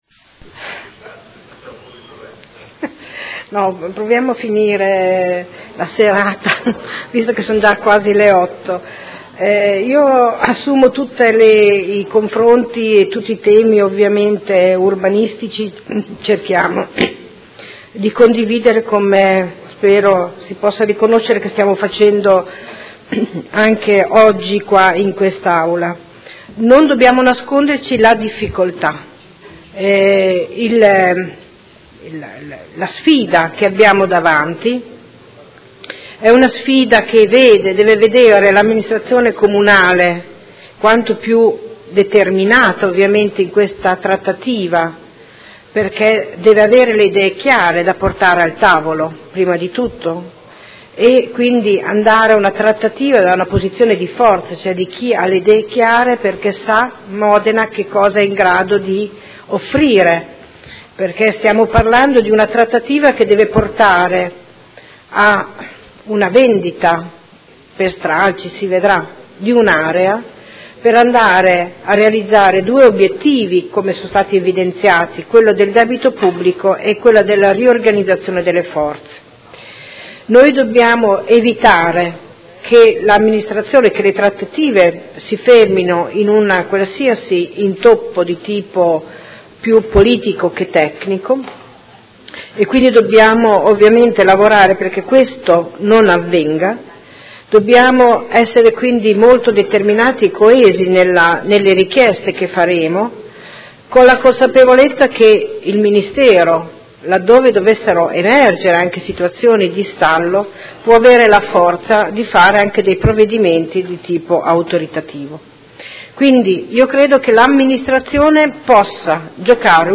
Seduta del 10/03/2016. Replica a dibattito su Proposta di Deliberazione e Ordini del Giorno sugli immobili militari presenti nel territorio comunale